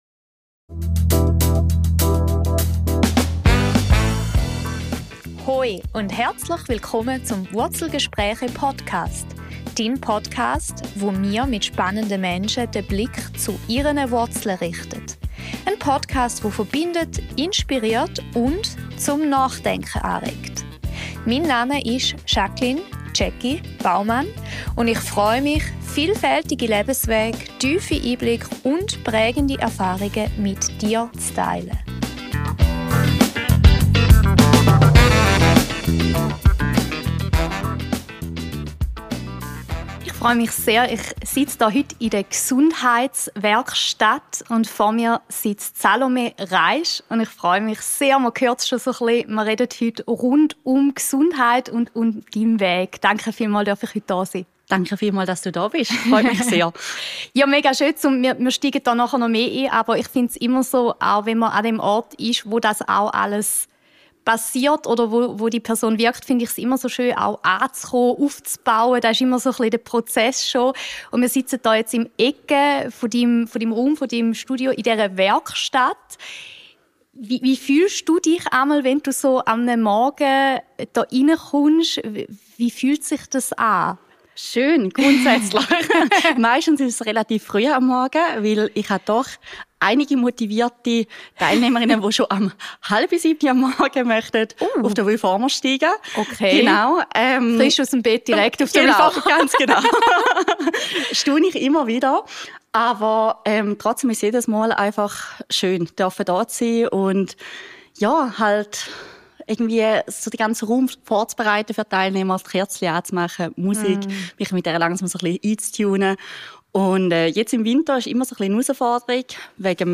Es geht um Balance, um ehrliche Einblicke hinter die Kulissen und um die Frage, wie Gesundheit ganzheitlich gedacht und gelebt werden kann. Ein Gespräch über das Entstehen von Räumen, die tragen – und über die Freude am Leben als Grundlage für nachhaltiges Wirken.